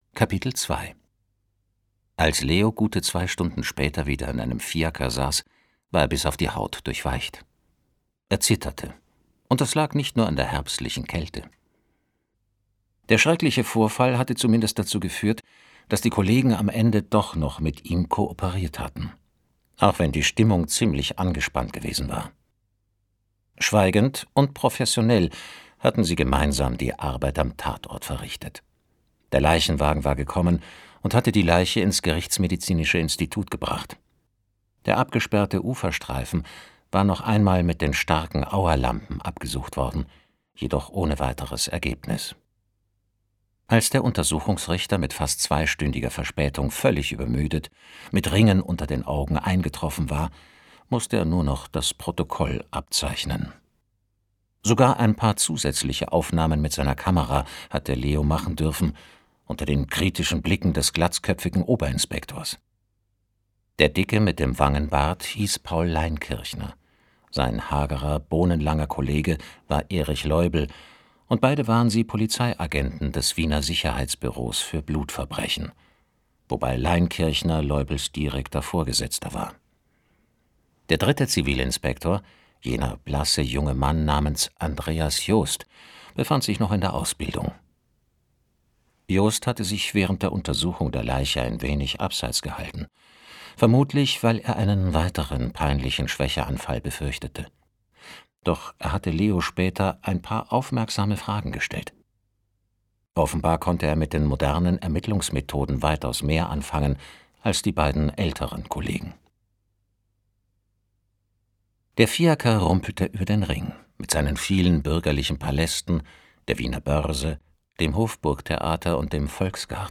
2021 | Gekürzte Lesung